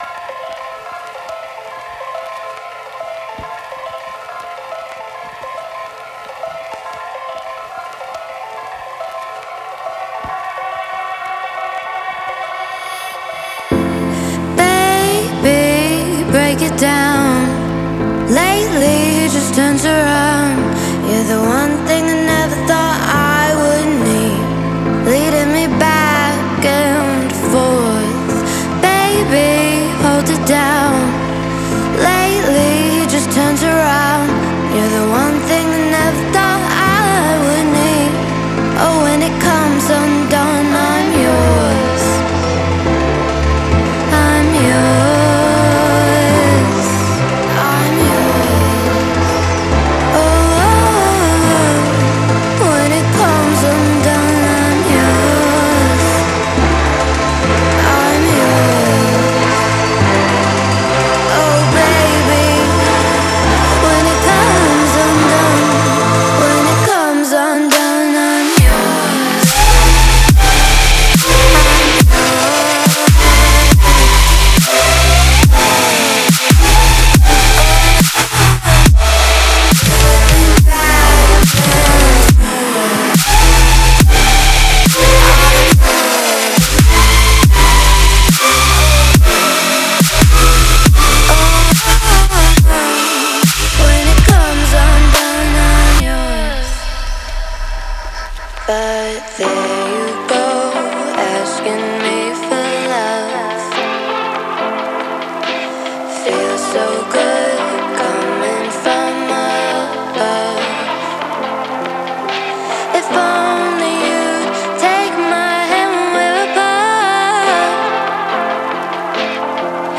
это завораживающая электронная композиция